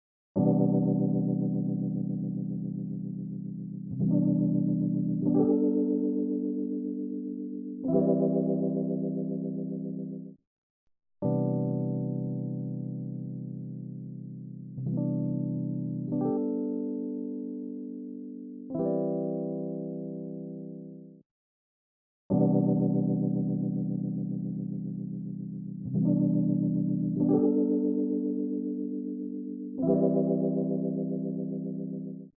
渦巻くクラシック・ロータリーサウンド
Rotary Mod | Rhodes | Preset: Big Fat Cabinet
Rotary-Eventide-Rhodes-Big-Fat-Cabinet-Mix50.mp3